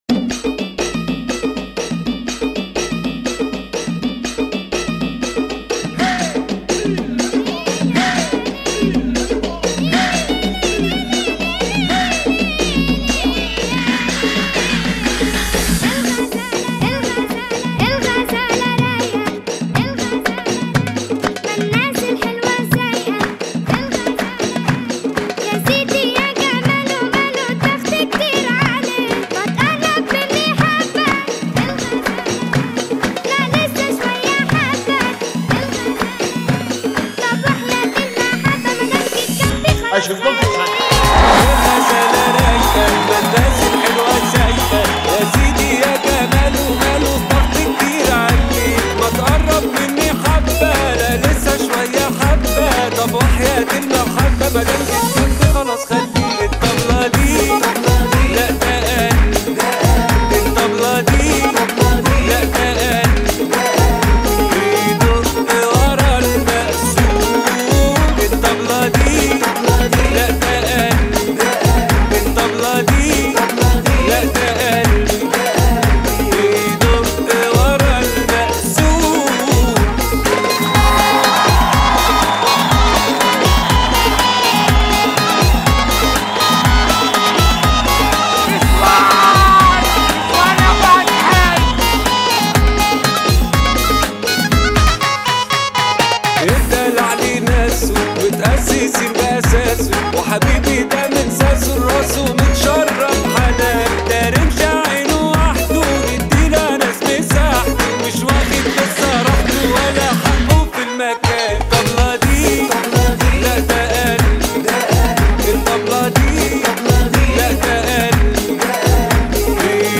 [ 122 bpm ]